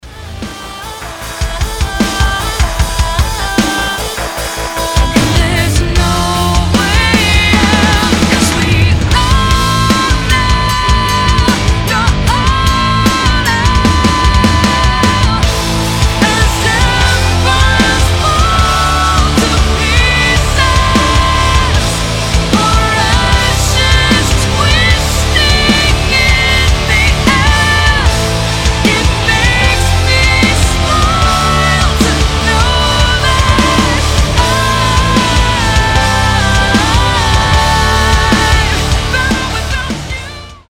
• Качество: 320, Stereo
громкие
Alternative Rock
сильный голос
тяжелый рок